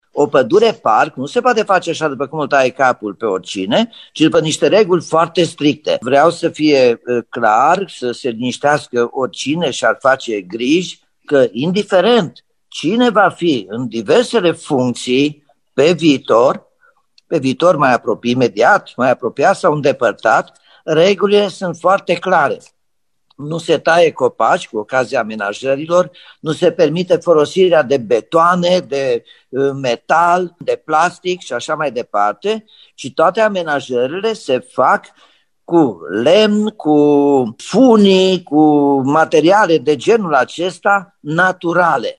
Primarul Nicolae Robu a explicat că regulile de amenajare a unui parc în pădurea verde sunt foarte stricte: